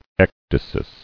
[ec·dy·sis]